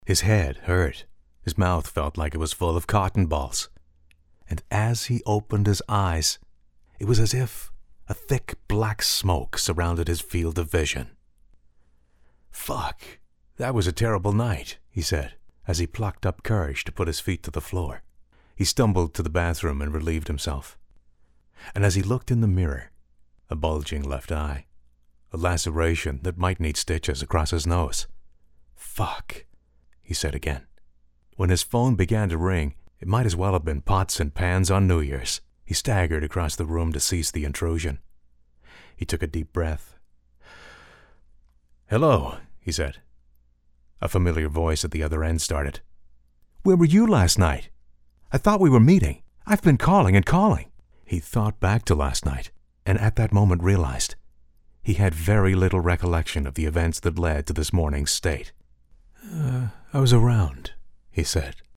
I wrote this to get a feel for narrating action/dramatic stories..